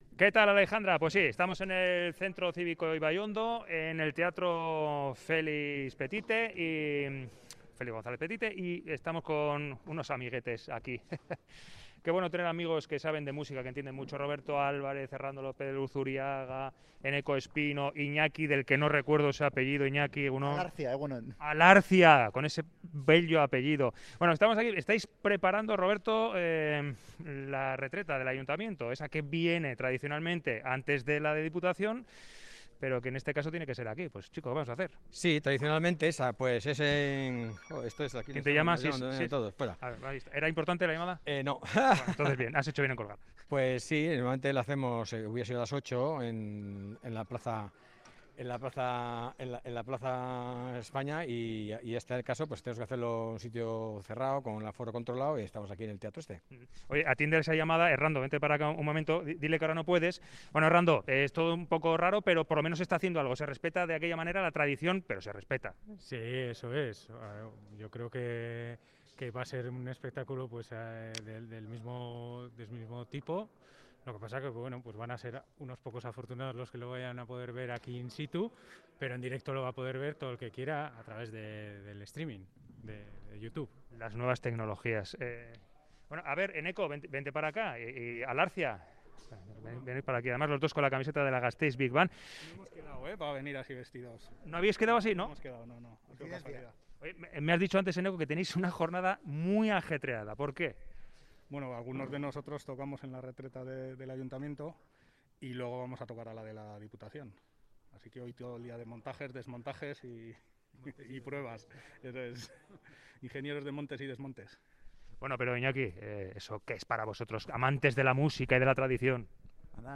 Nos colamos en los ensayos de la Retreta de Musiketxea